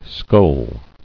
[skoal]